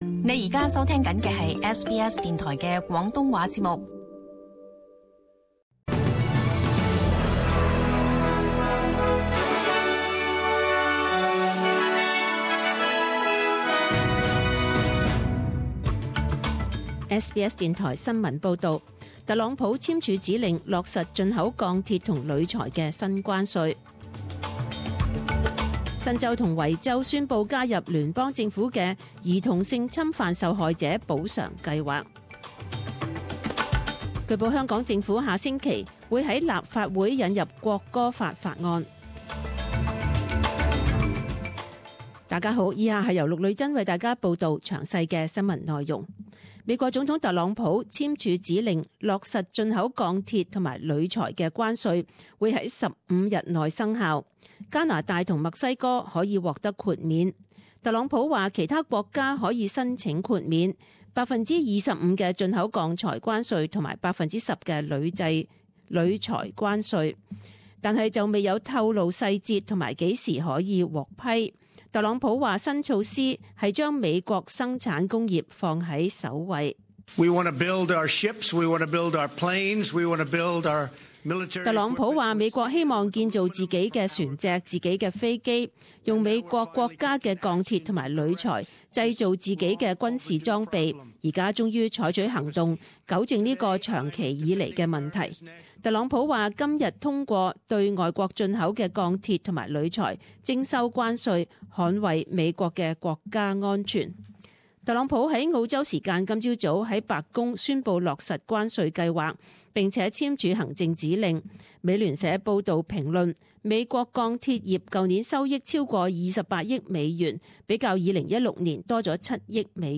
SBS中文新闻 （三月九日）
请收听本台为大家准备的详尽早晨新闻。